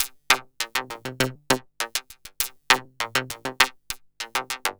tx_synth_100_twangs_CDAbG2.wav